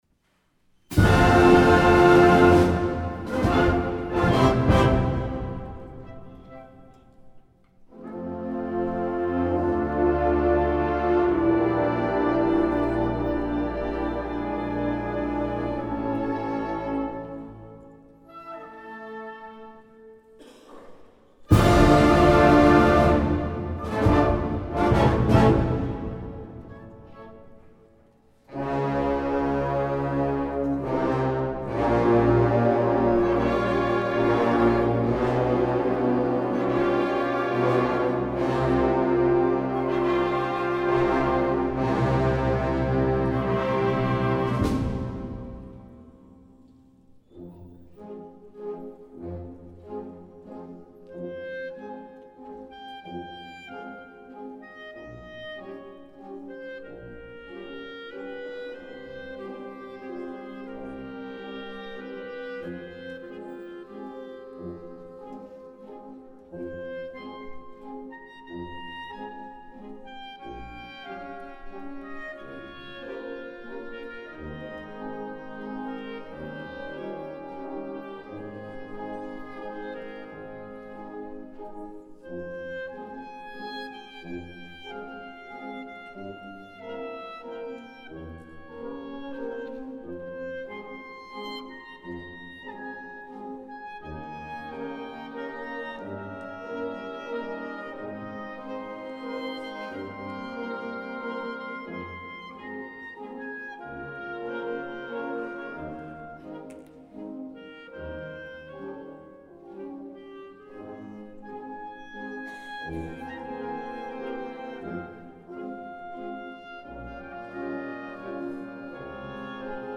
West Monroe High School Band 2018-2019
Spring Concert